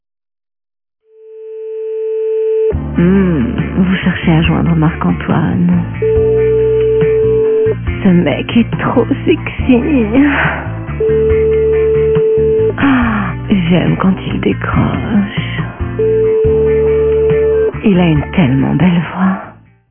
- Personnalisez votre tonalité d’attente et faites patienter vos correspondants autrement ! -